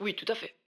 VO_ALL_Interjection_17.ogg